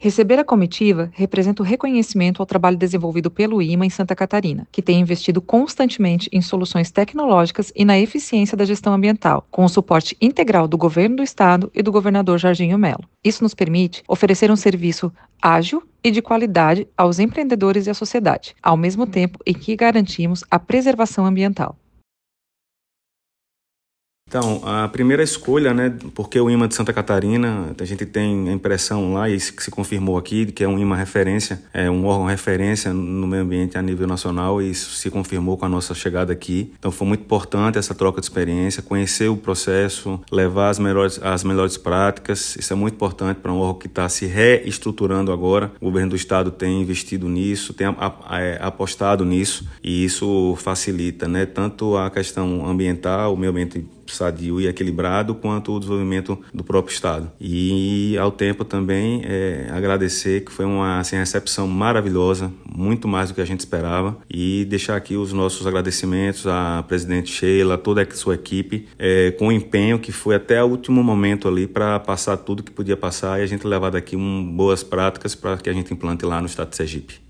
SONORA – IMA apresenta modelo de gestão Ambiental de Santa Catarina à comitiva do Sergipe
A presidente do IMA, Sheila Meirelles, destacou a importância do instituto ter esse reconhecimento a nível nacional:
O presidente da Adema/SE, Carlos Anderson Silveira Pedreira, ressaltou que a visita técnica foi essencial para que a equipe pudesse conhecer as boas práticas de gestão do IMA: